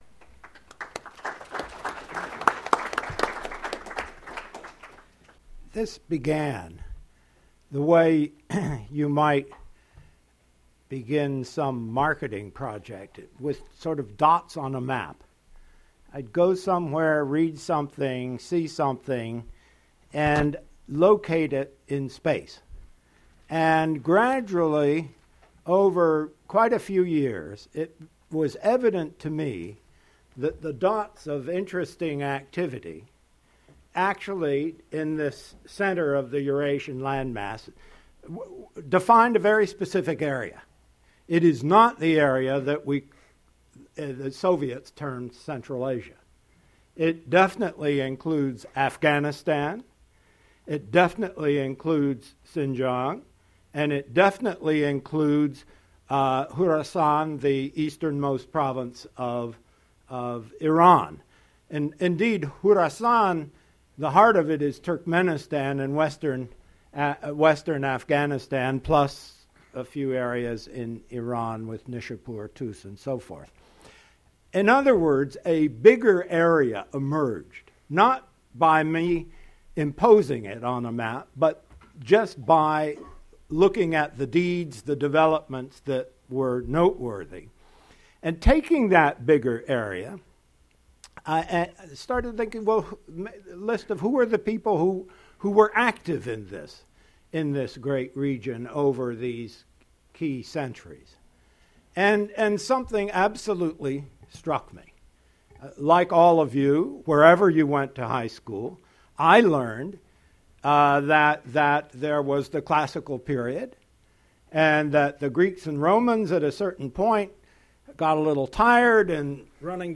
Lost Enlightenment-Central Asia's Golden Age - Book by Fred Starr - CACI event 10-23-13